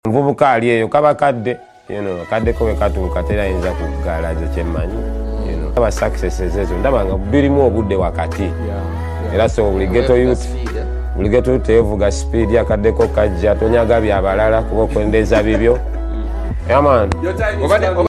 Multi purpose gym trainer machine sound effects free download